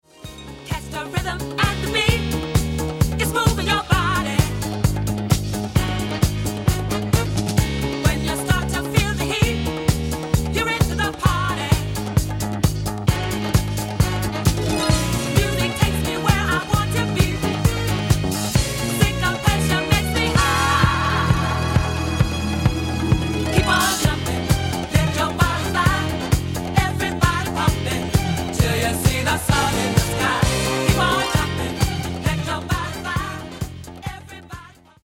Genere:   Disco | Funk
Registrato al Blank Tape Studios New York